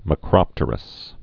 (mə-krŏptər-əs)